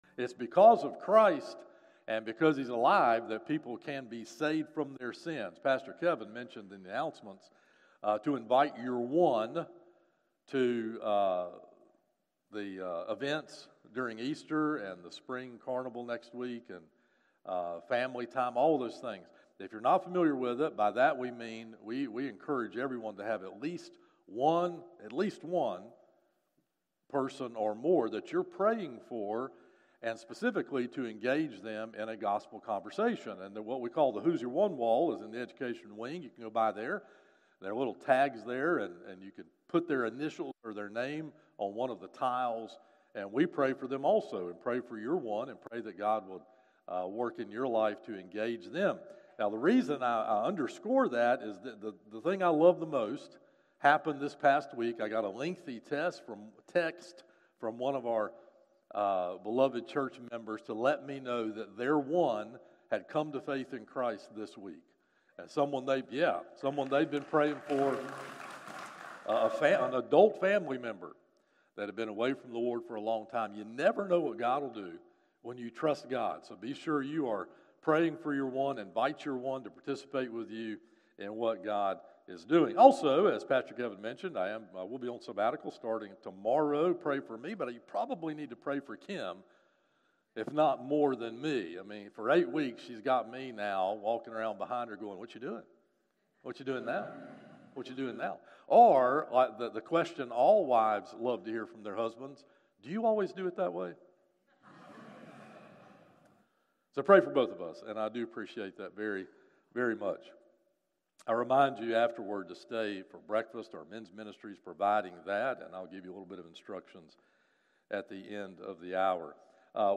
7 AM SONRise Service